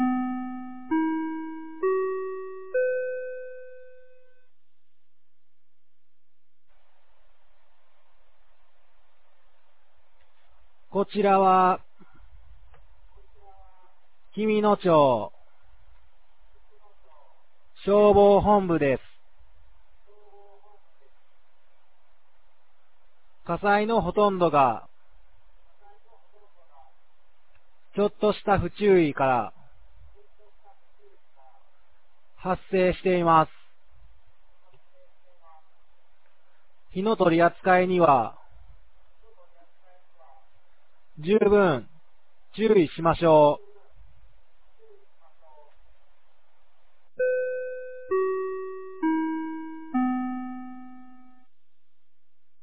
2024年02月17日 16時00分に、紀美野町より全地区へ放送がありました。